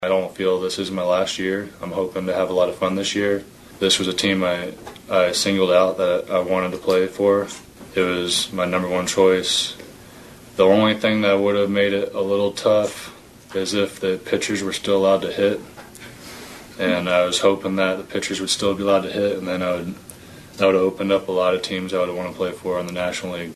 Zack met with the media Thursday and said Kansas City is the place he wants to be.